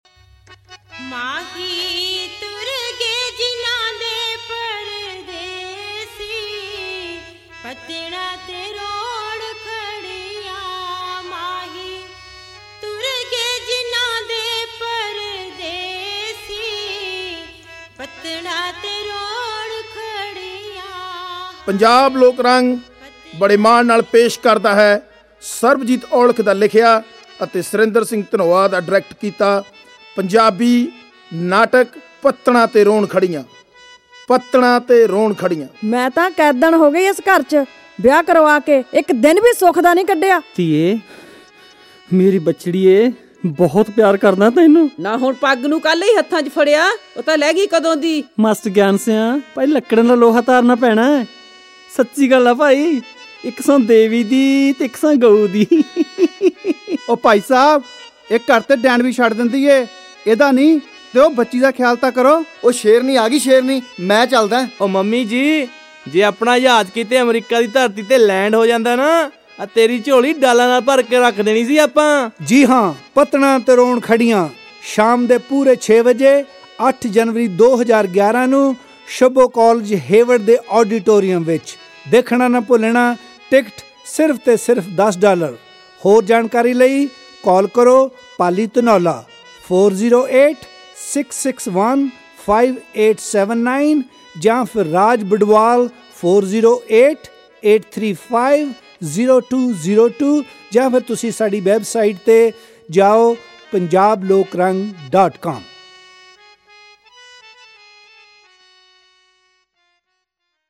Play Audio Ad
Orginal new commercial.mp3